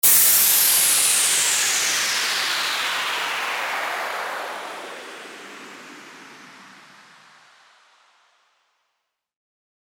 FX-1740-WHOOSH
FX-1740-WHOOSH.mp3